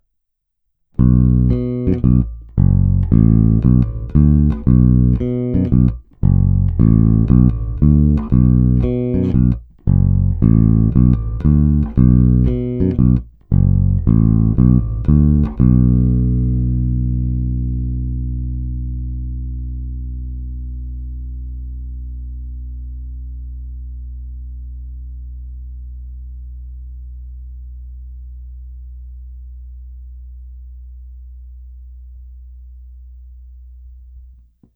Není-li uvedeno jinak, následující nahrávky jsou provedeny rovnou do zvukové karty, v pasívním režimu a s plně otevřenou tónovou clonou.
Hráno nad použitým snímačem, v případě obou hráno mezi nimi.
Oba snímače